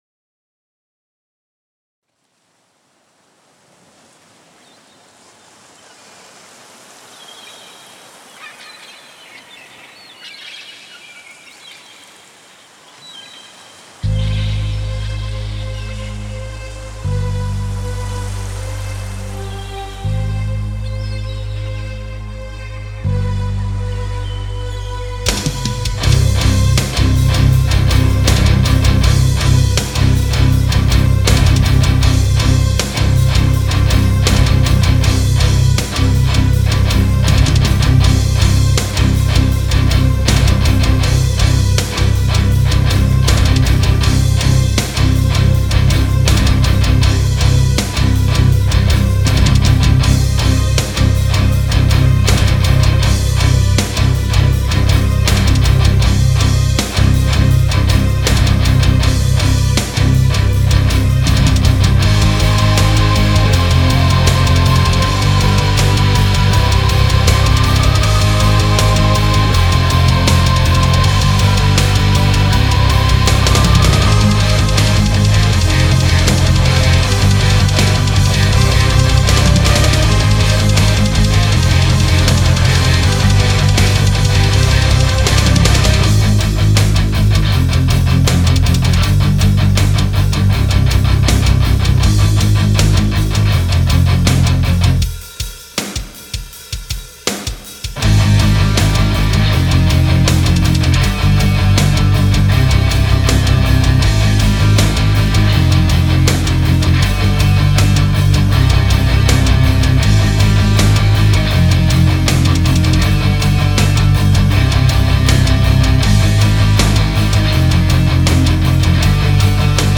Буду рад советам по записи - 2 (металл)